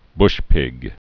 (bshpĭg)